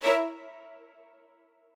strings11_6.ogg